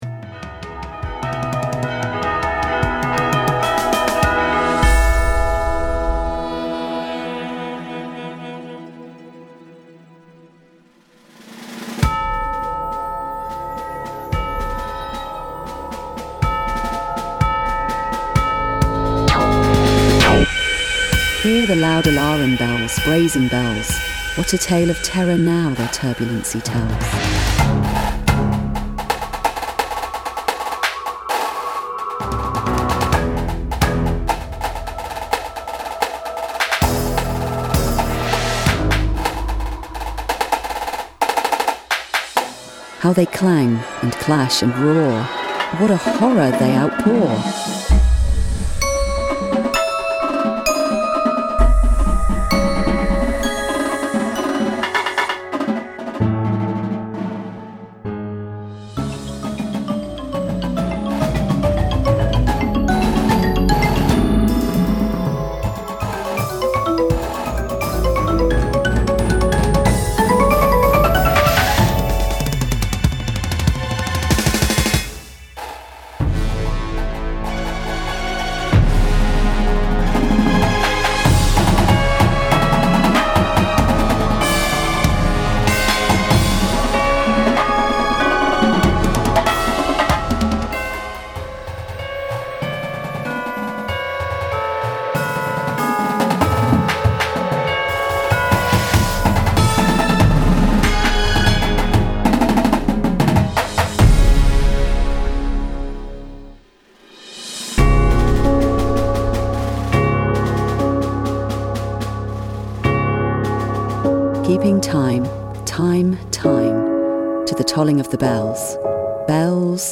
Indoor Percussion Shows
Front Ensemble